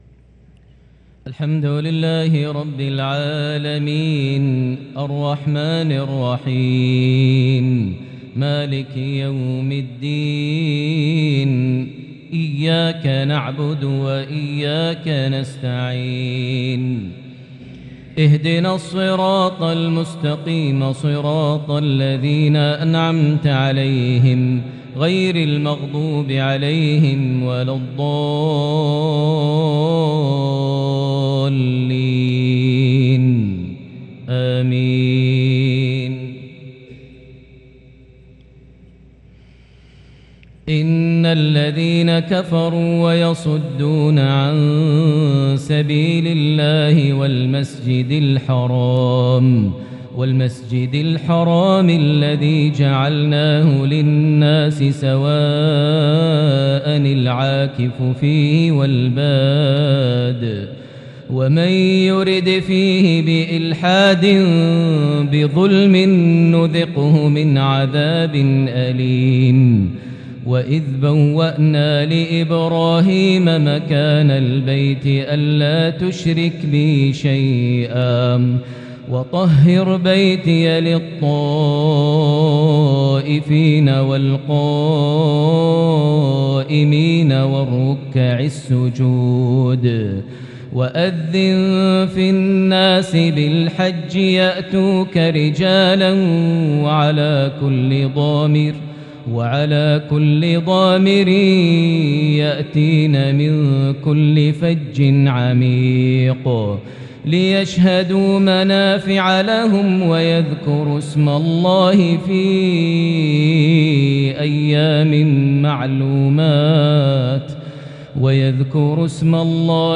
lsha 1-7-2022 prayer from Surah Al-Hajj 25-37 > 1443 H > Prayers - Maher Almuaiqly Recitations